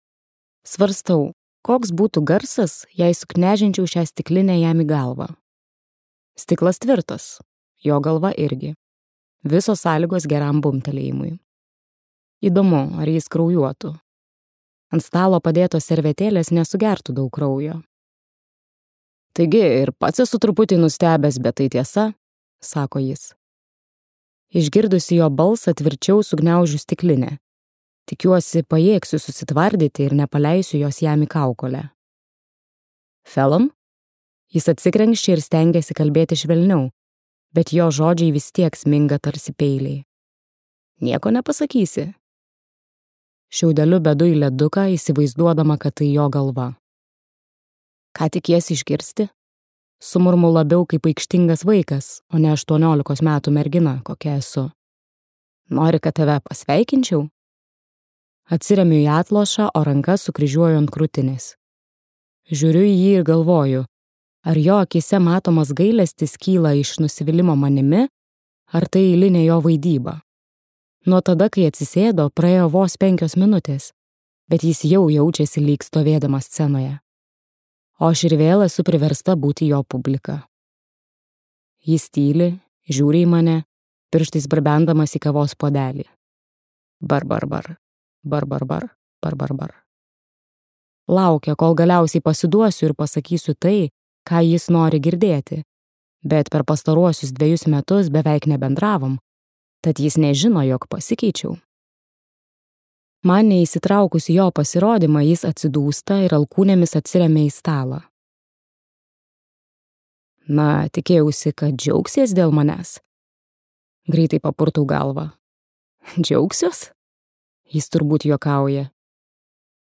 Lapkričio 9 | Audioknygos | baltos lankos